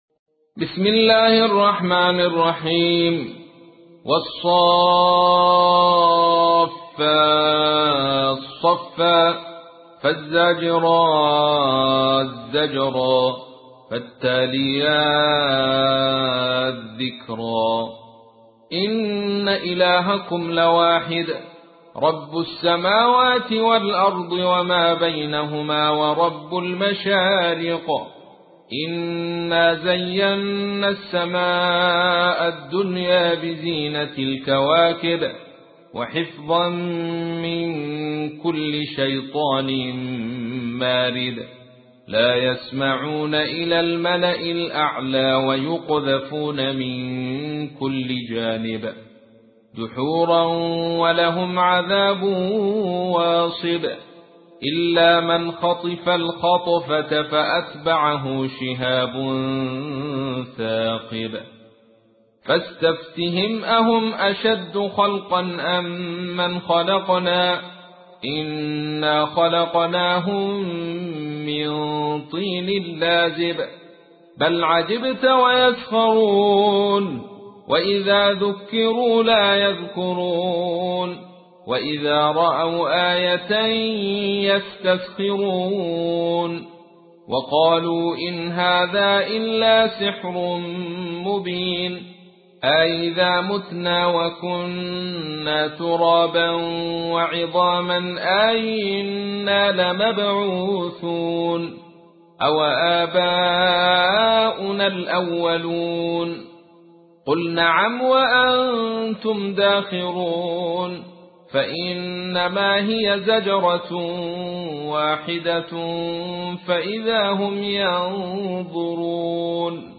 تحميل : 37. سورة الصافات / القارئ عبد الرشيد صوفي / القرآن الكريم / موقع يا حسين